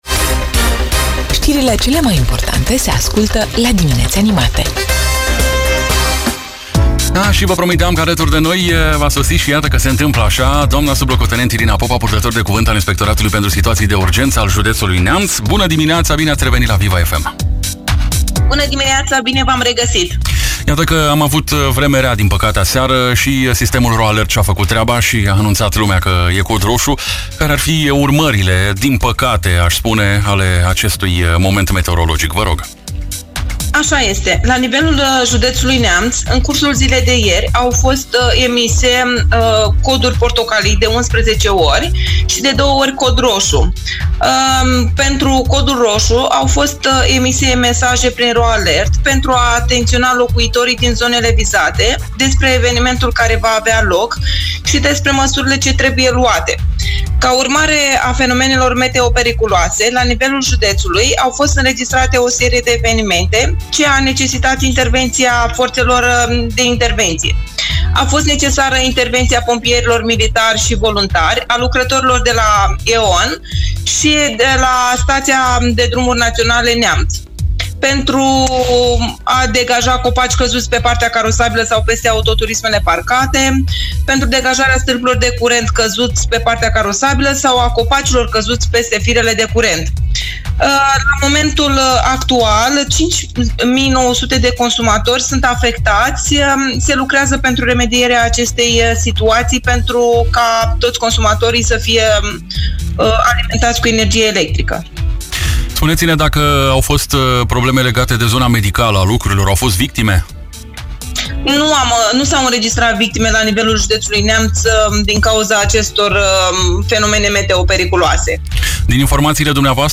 Amănunte ne-a oferit, în direct la “Dimineți Animate”, pe Viva FM Neamț